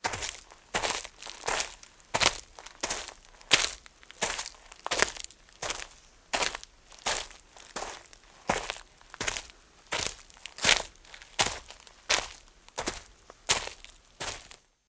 SFX雪地中行走积雪卡通行为音效下载